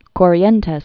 (kôrē-ĕntĕs)